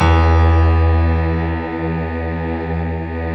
SI1 PIANO00L.wav